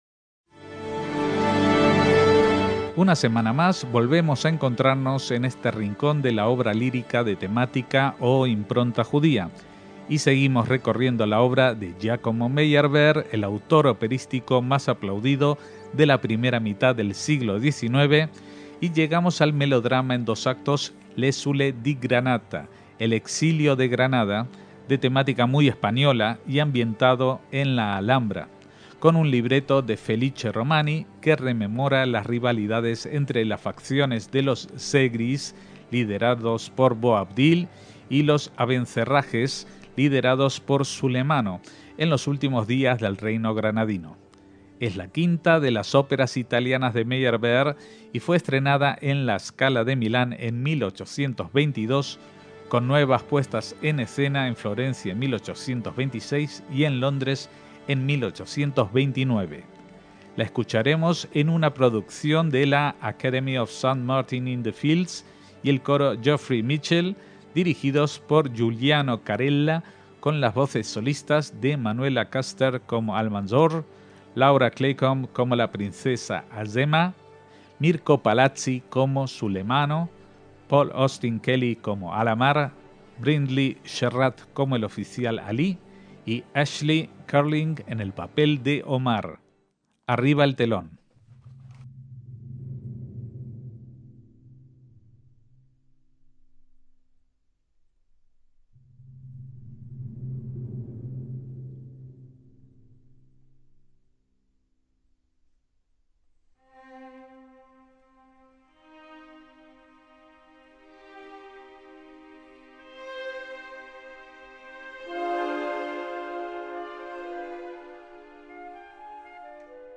ÓPERA JUDAICA
es una ópera en dos actos